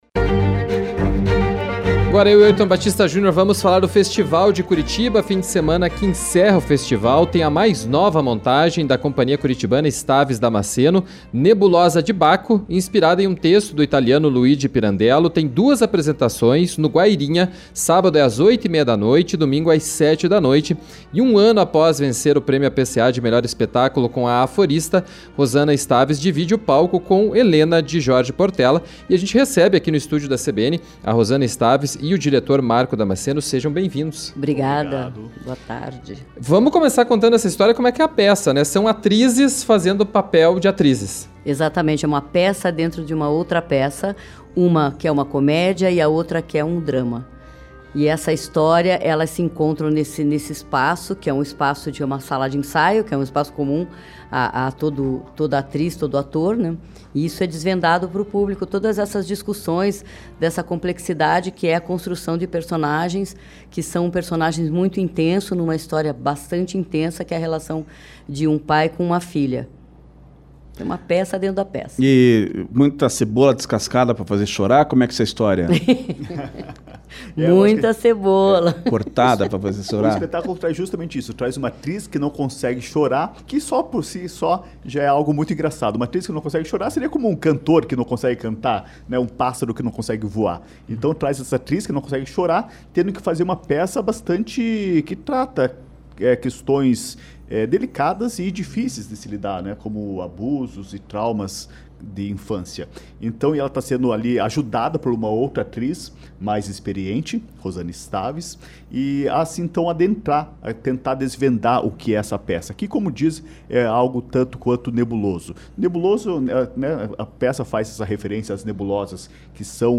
estiveram nos estúdios da CBN Curitiba para falar sobre a peça